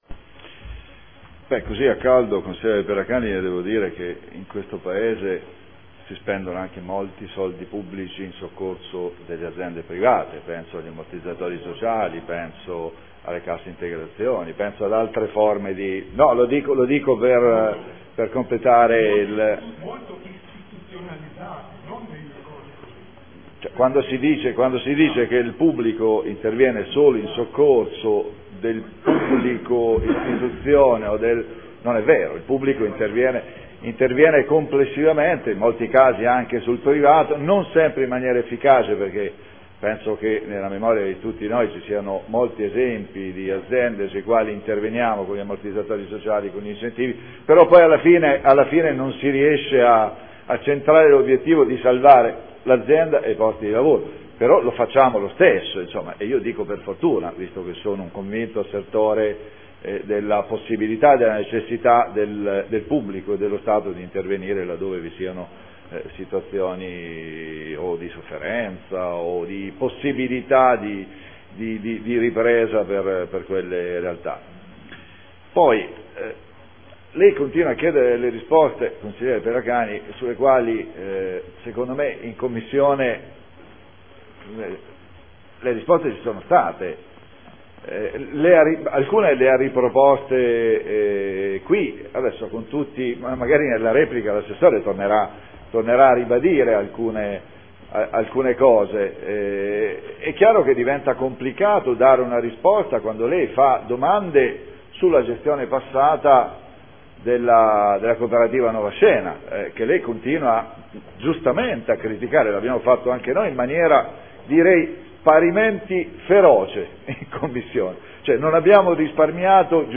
Paolo Trande — Sito Audio Consiglio Comunale
Seduta del 23/01/2014 Adesione del Comune di Bologna a Emilia Romagna Teatro Fondazione in qualità di socio fondatore necessario.